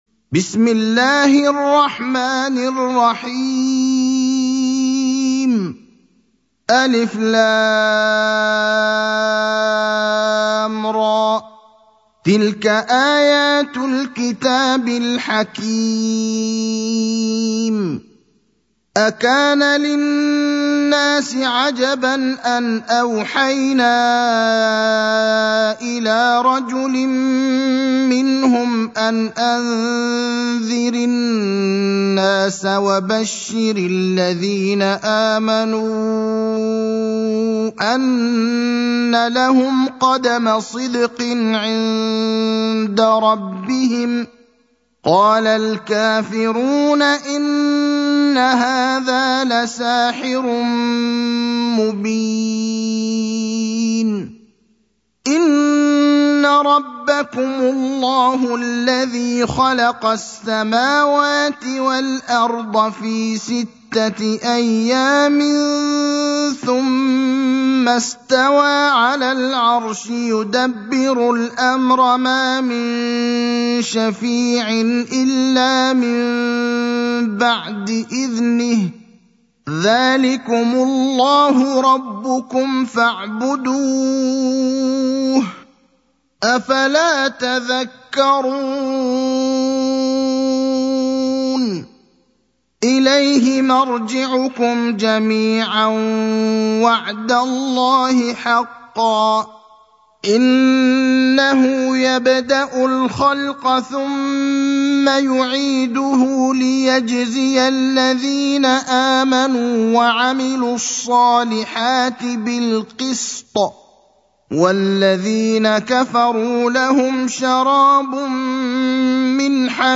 المكان: المسجد النبوي الشيخ: فضيلة الشيخ إبراهيم الأخضر فضيلة الشيخ إبراهيم الأخضر يونس (10)
مصحف الشيخ إبراهيم الأخضر